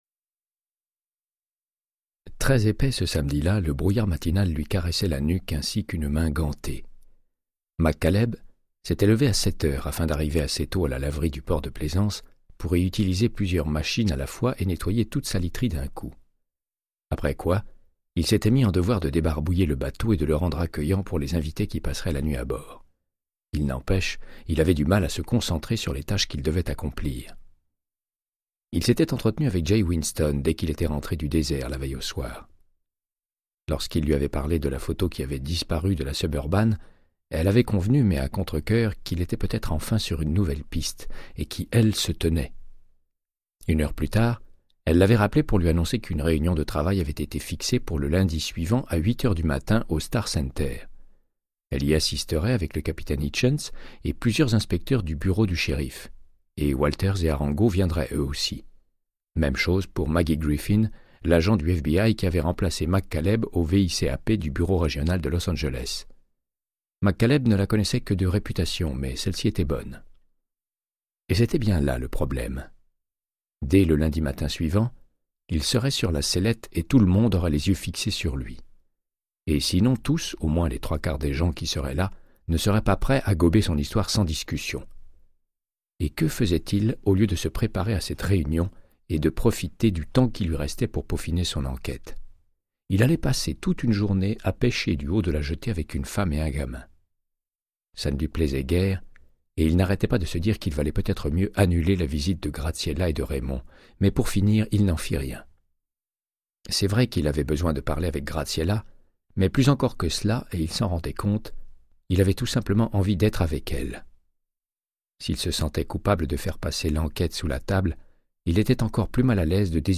Audiobook = Créance de sang, de Michael Connellly - 86